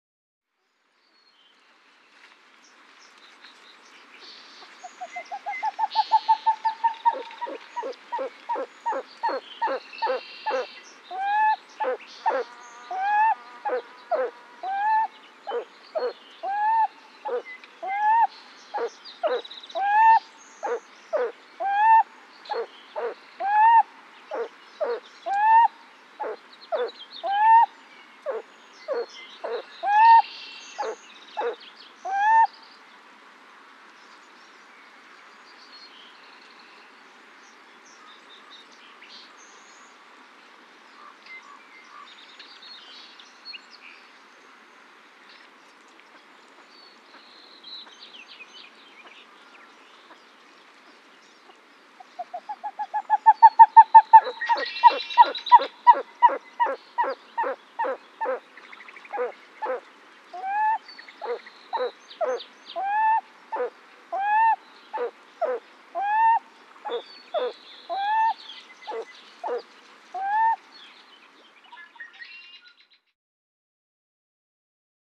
Grebe, Pied-billed Calls. Laughing Bird Calls In The Foreground With Raven And Other Bird Calls In The Background. Ocean Ambience.